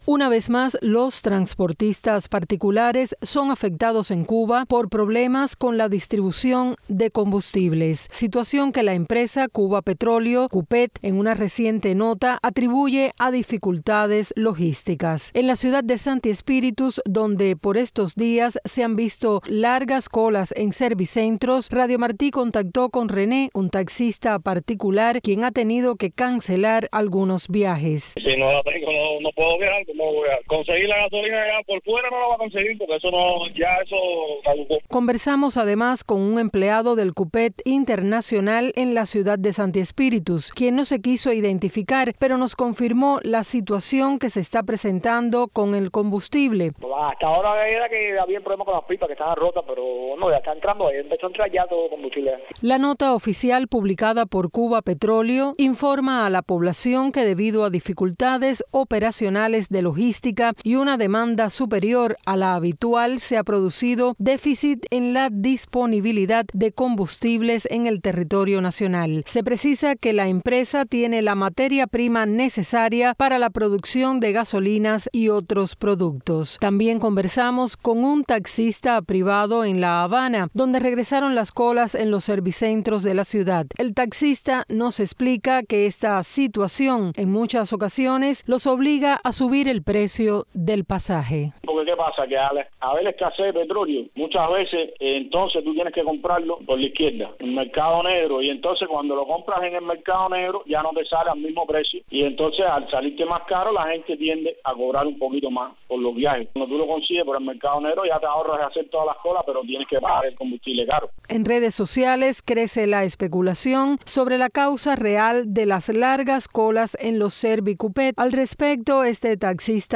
Transportistas cubanos opinan sobre falta de combustible